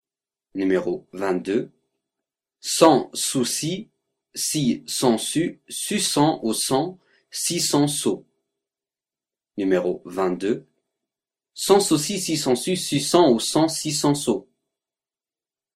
22 Virelangue